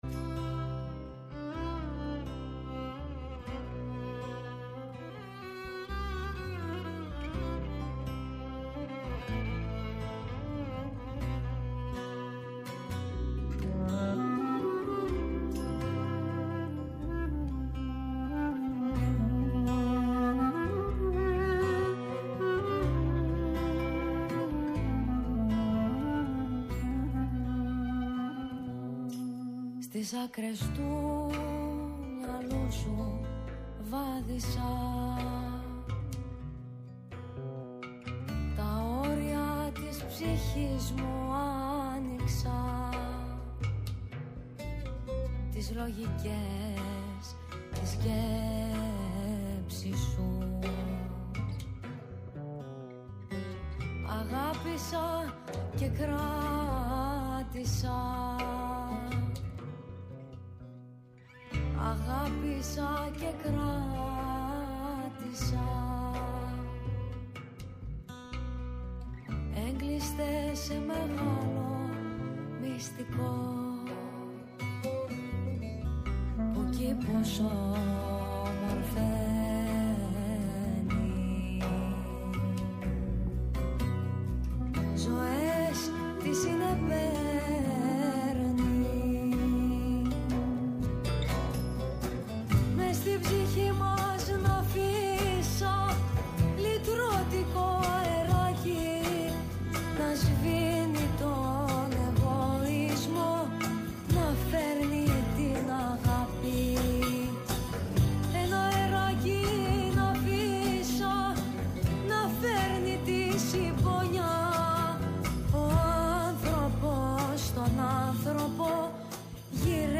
ΔΕΥΤΕΡΟ ΠΡΟΓΡΑΜΜΑ Παντος Καιρου Αφιερώματα Μουσική Συνεντεύξεις